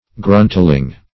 Meaning of gruntling. gruntling synonyms, pronunciation, spelling and more from Free Dictionary.
Gruntling \Grunt"ling\ (gr[u^]nt"l[i^]ng), n.
gruntling.mp3